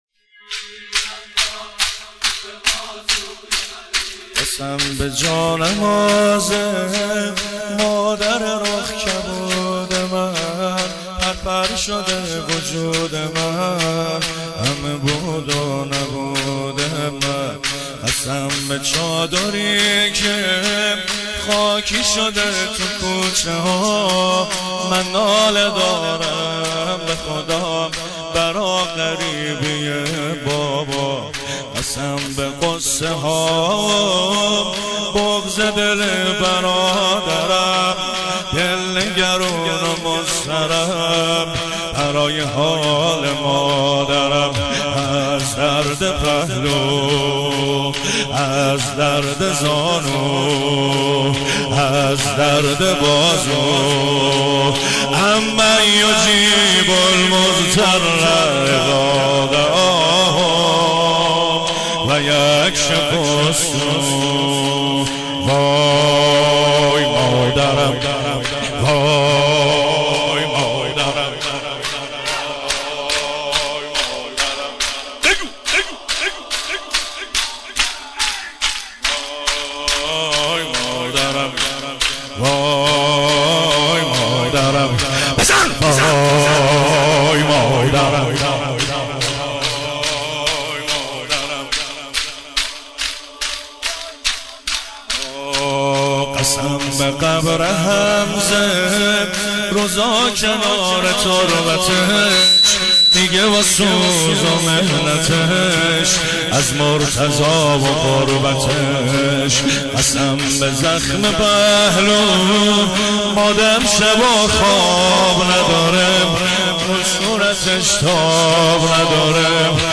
سینه زنی در شهادت بانوی بی نشان حضرت زهرا(س